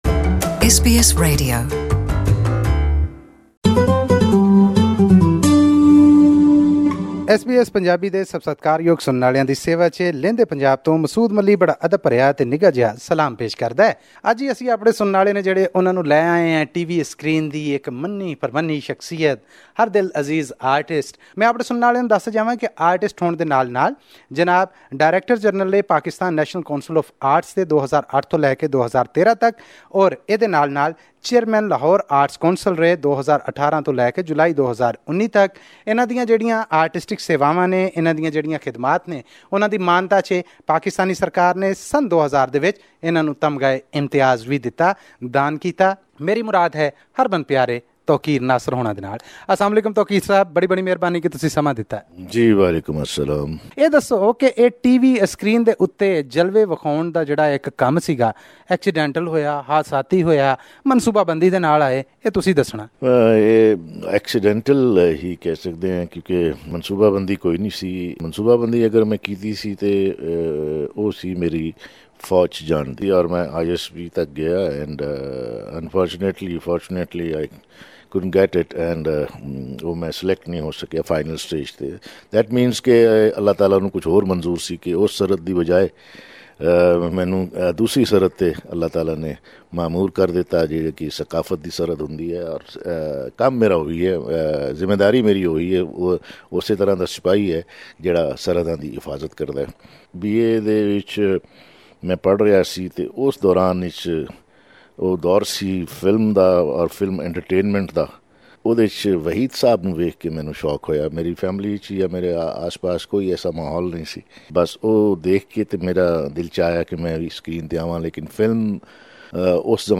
In our fortnightly interview series with a famous personality from Pakistan's Punjab, this week, we bring to you popular senior actor, Tauqeer Nasir.
Tauqeer Nasir speaks with SBS Punjabi.